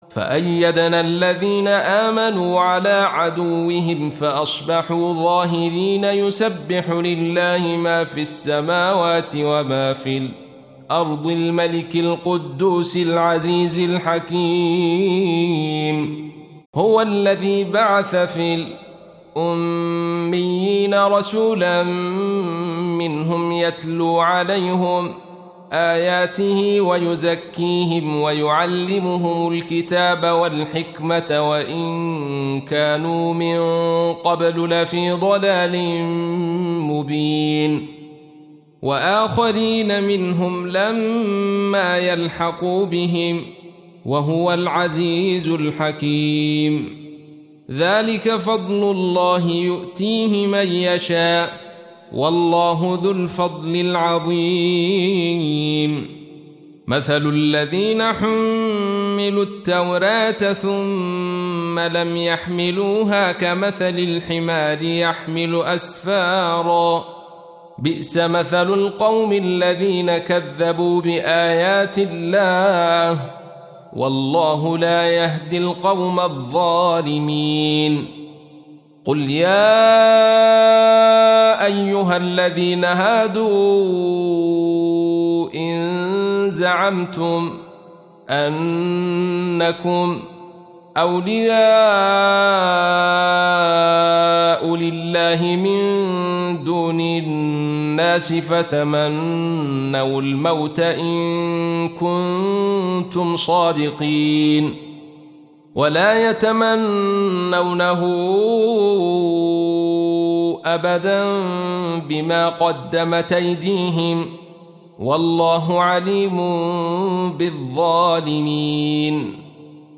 Surah Repeating تكرار السورة Download Surah حمّل السورة Reciting Murattalah Audio for 62. Surah Al-Jumu'ah سورة الجمعة N.B *Surah Includes Al-Basmalah Reciters Sequents تتابع التلاوات Reciters Repeats تكرار التلاوات